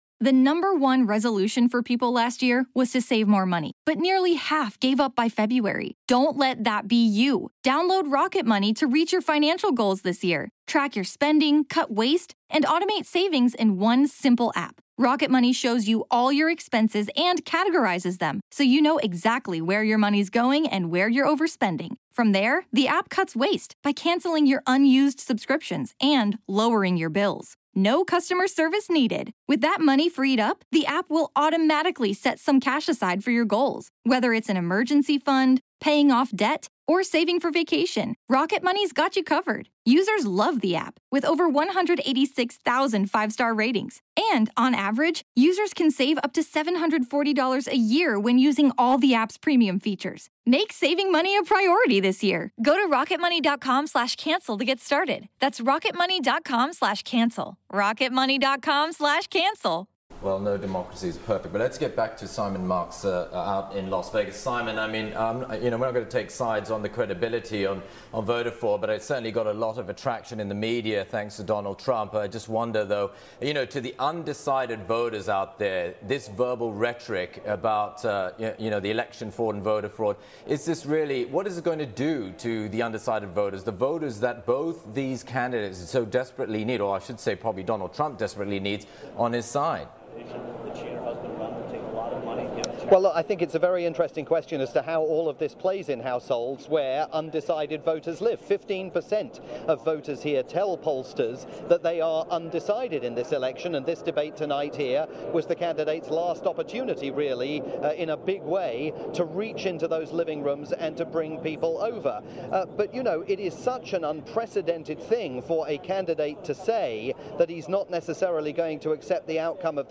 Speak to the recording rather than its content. instant analysis of the third Clinton / Trump debate in Las Vegas as it aired on Asia's leading TV news network Channel NewsAsia.